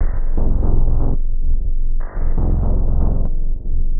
Watching (Bass) 120BPM.wav